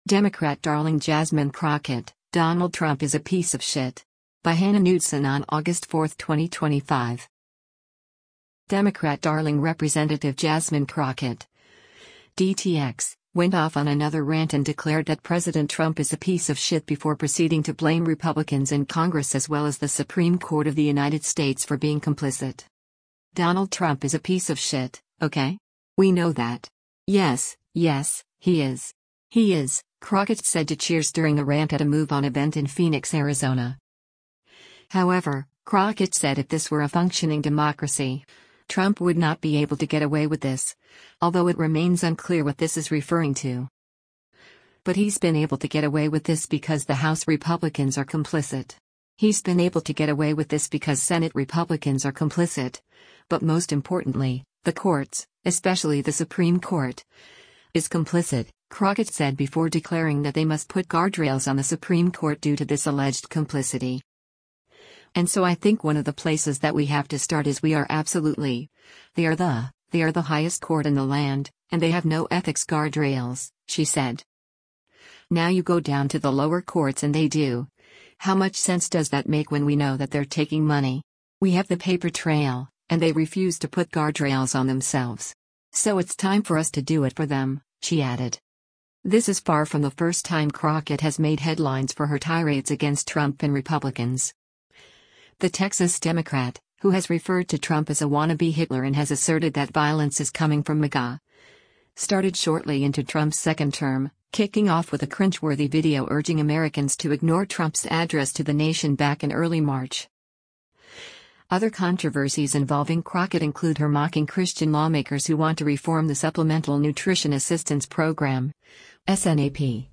U.S. Rep. Jasmine Crockett (D-TX) speaks during the We Choose To Fight: Nobody Elected Elo
“Donald Trump is a piece of shit, ok? We know that. Yes — yes, he is. He is,” Crockett said to cheers during a rant at a MoveOn event in Phoenix, Arizona.